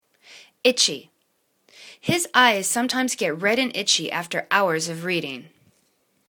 itch.y     /'ichi/    adj